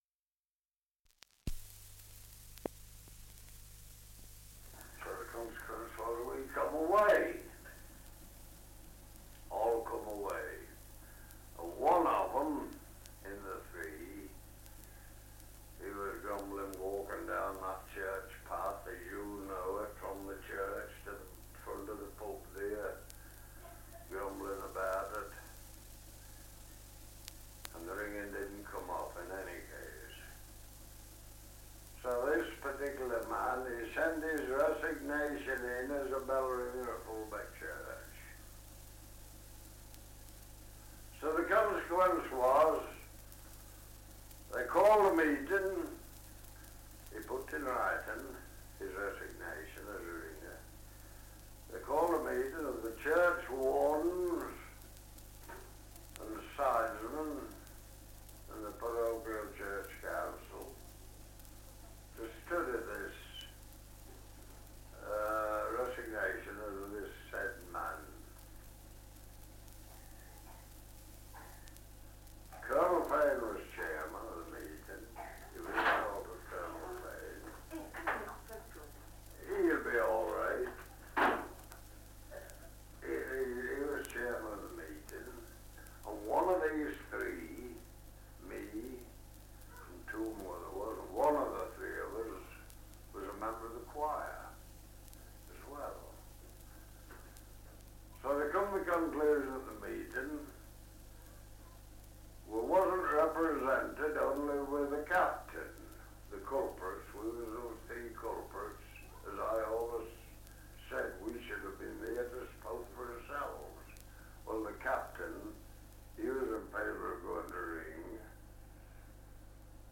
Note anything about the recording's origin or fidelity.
Survey of English Dialects recording in Fulbeck, Lincolnshire 78 r.p.m., cellulose nitrate on aluminium